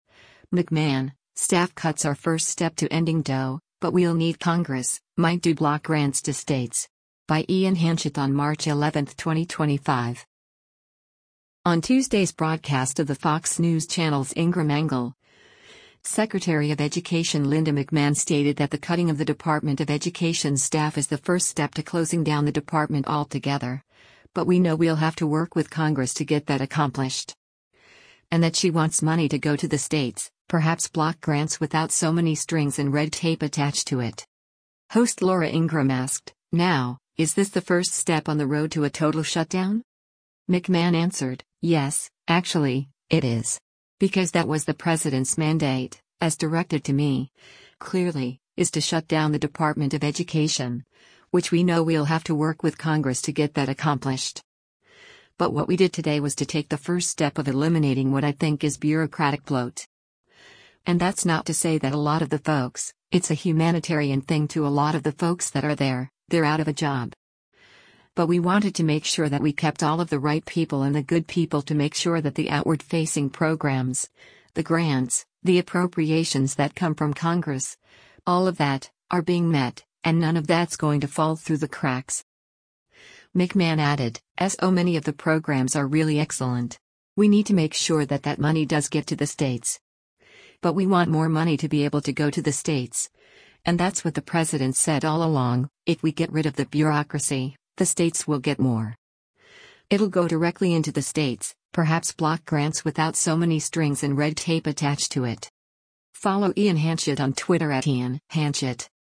On Tuesday’s broadcast of the Fox News Channel’s “Ingraham Angle,” Secretary of Education Linda McMahon stated that the cutting of the Department of Education’s staff is the first step to closing down the department altogether, but “we know we’ll have to work with Congress to get that accomplished.”
Host Laura Ingraham asked, “Now, is this the first step on the road to a total shutdown?”